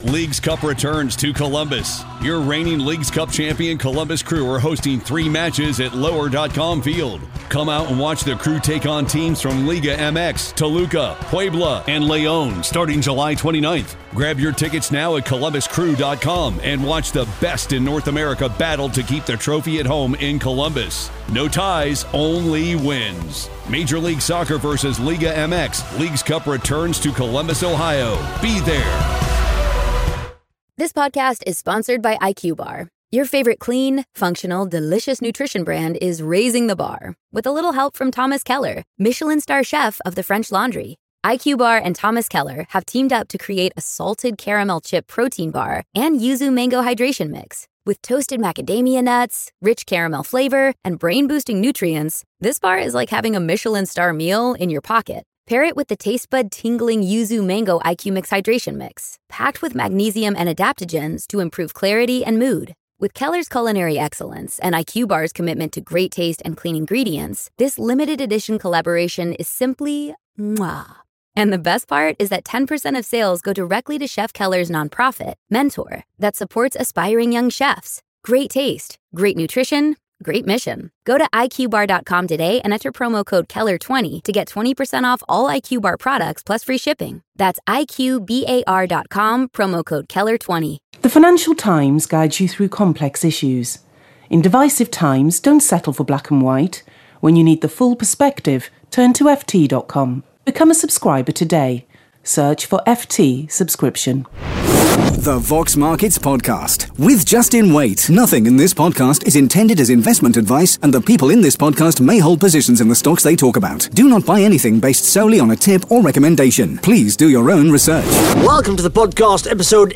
(Interview starts at 1 minute 50 seconds)